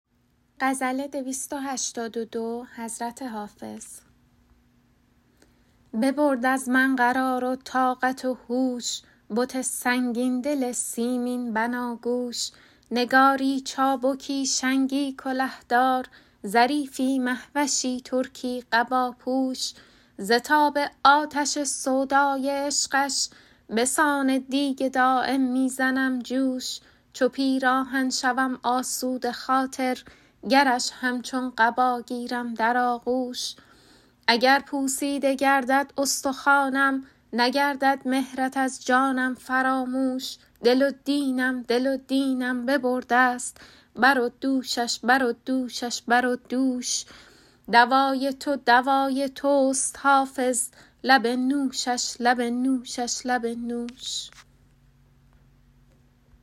حافظ غزلیات غزل شمارهٔ ۲۸۲ به خوانش